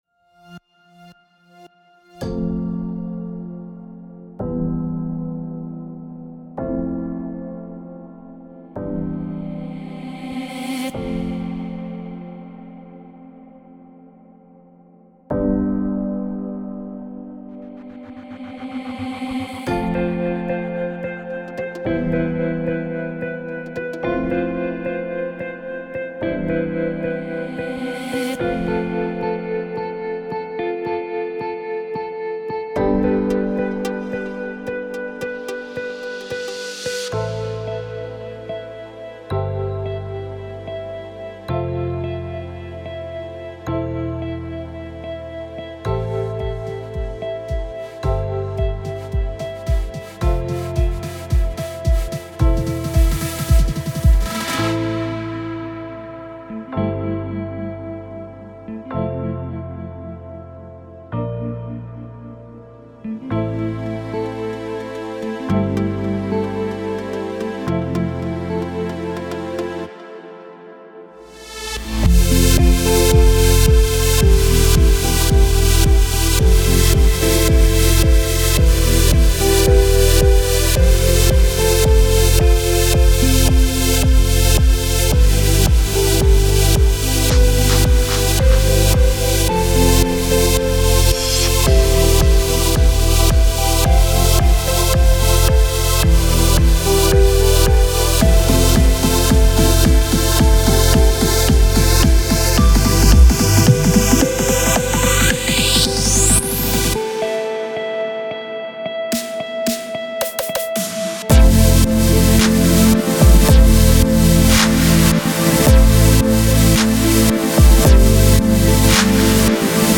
קאבר כזה